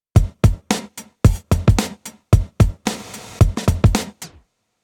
But using the mother reverb, the moment you let go the reverb is gone instantly.